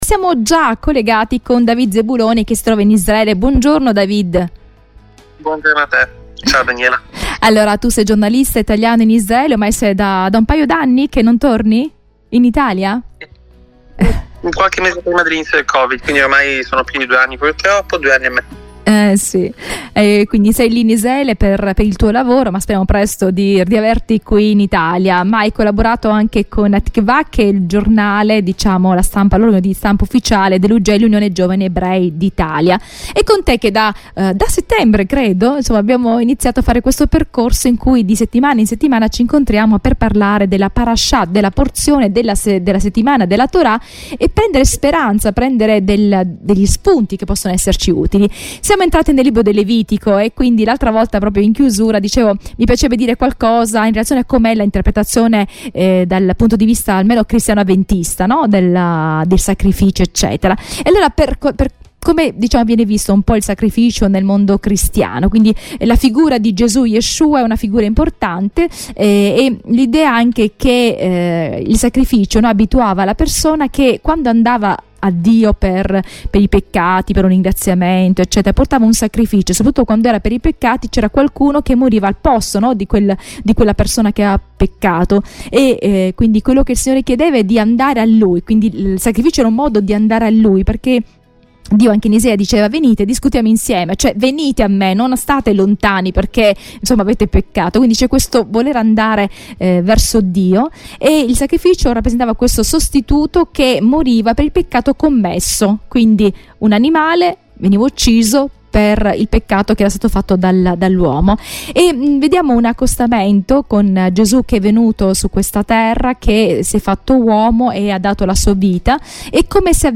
Segui il dialogo e cogli la speranza dalla Scrittura.